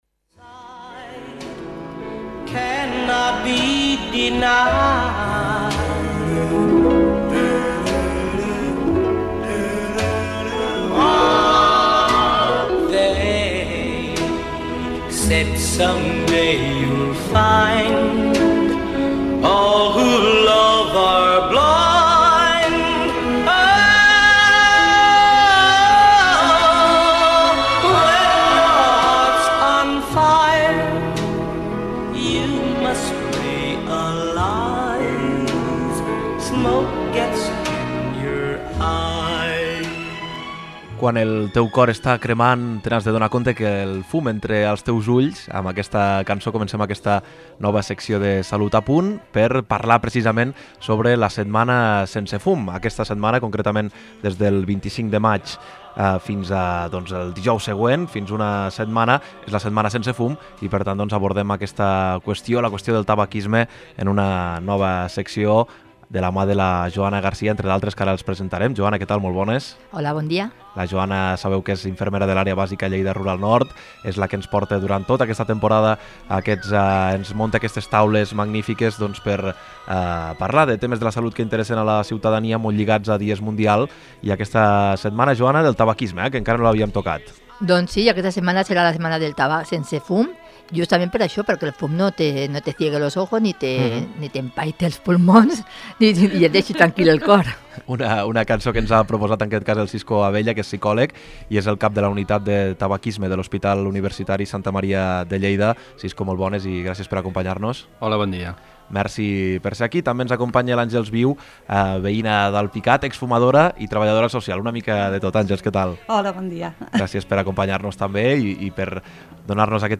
Per escoltar l’entrevista a ALPICAT RÀDIO, feu clic en ENTREVISTA.